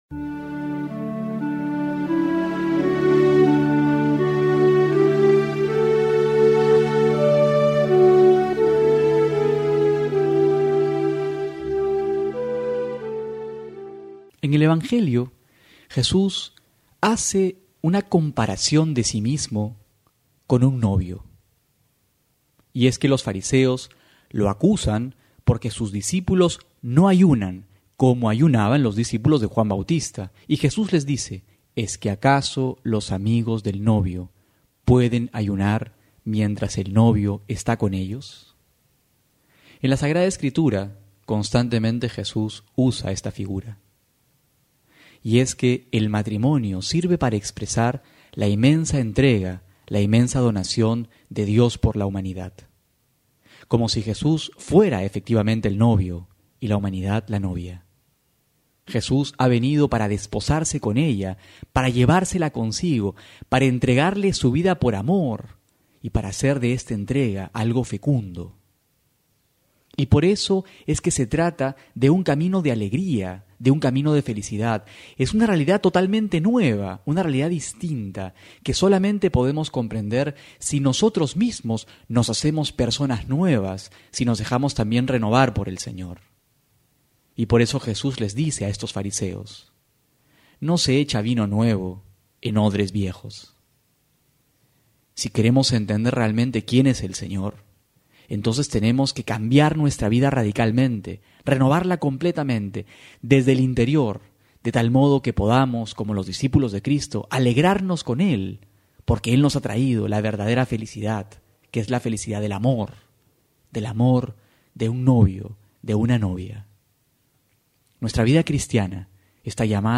Homilía para hoy: Marcos 1,12-15
febrero26-12homilia.mp3